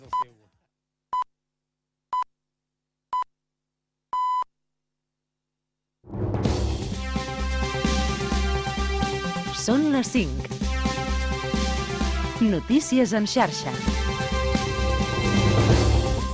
Senyals horaris i careta el butlletí de notícies